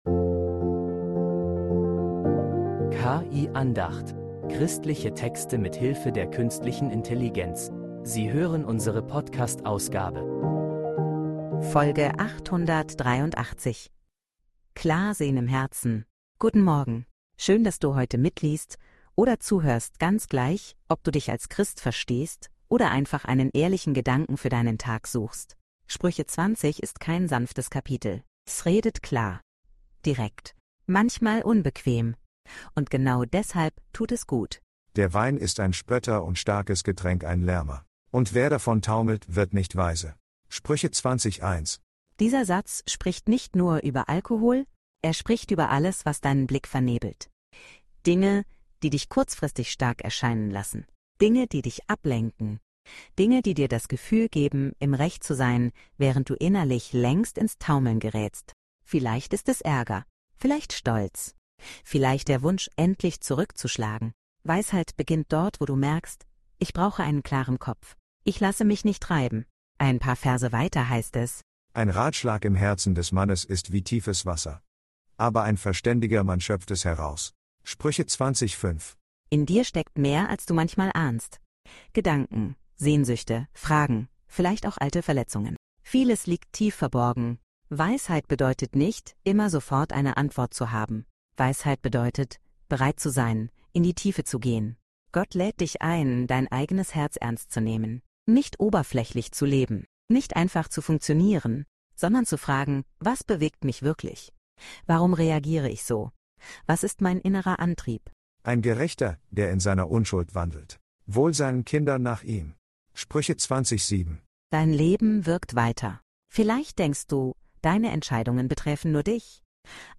Eine Andacht, die dich einlädt, heute bewusst zu handeln